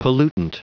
Prononciation du mot pollutant en anglais (fichier audio)
Prononciation du mot : pollutant